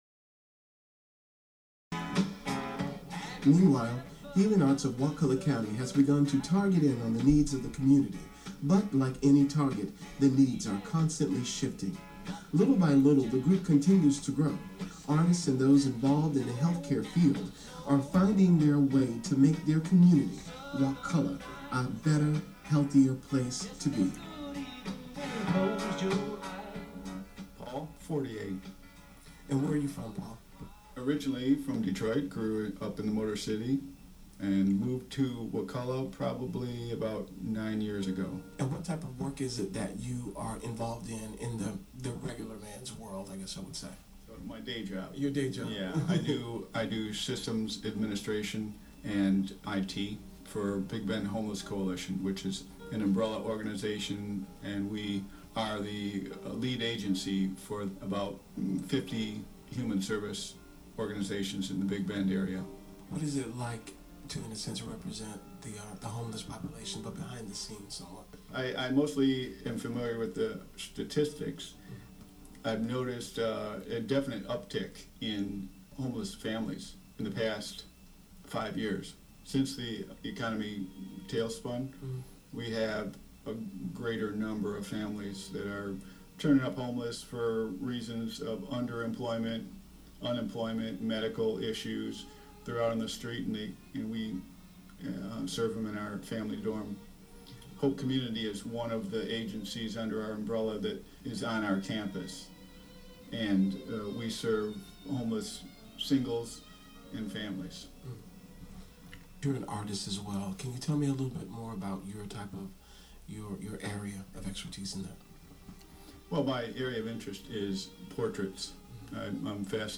Technology Arts Training Program Radio Interview May, 2012